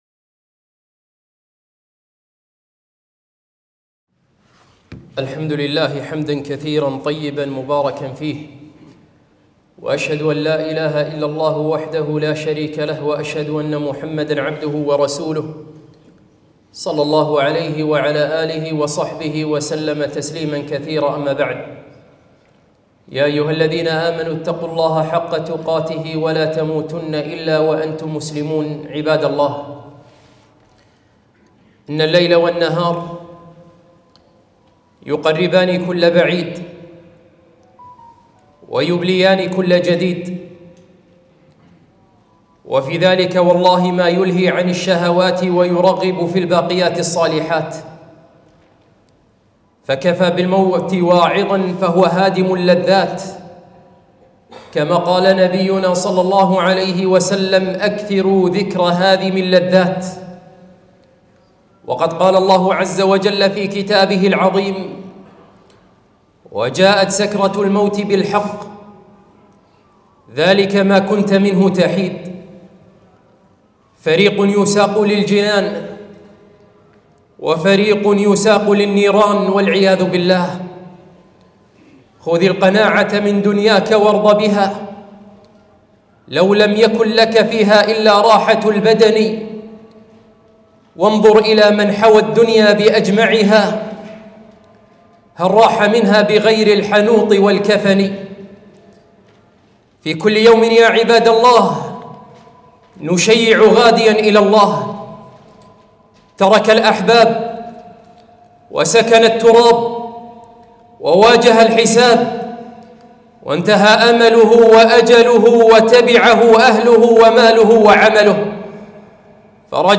خطبة - الـمـوت